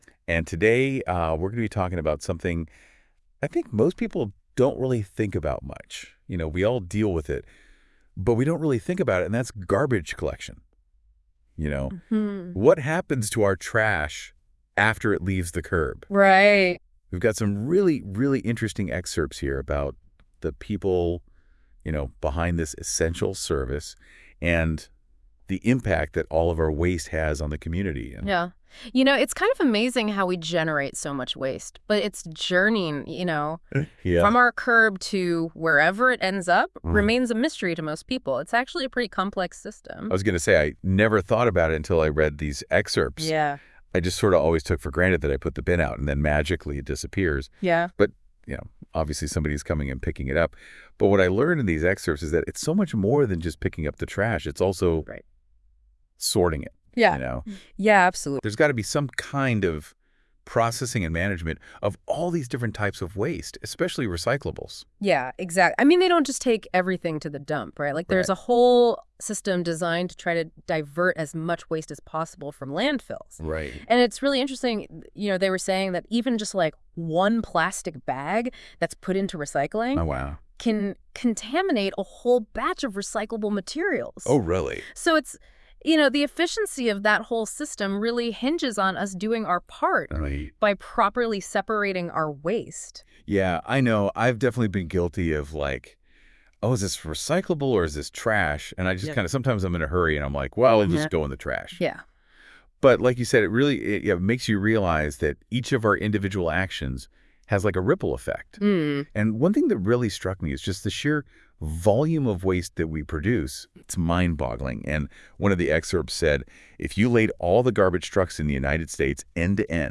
Tiếng Anh 9 – Unit 1: Local communication – Dialogue 4